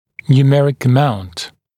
[njuː’merɪk ə’maunt][нйу:’мэрик э’маунт]сумма числовых показателей